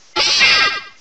The cries from Chespin to Calyrex are now inserted as compressed cries
perrserker.aif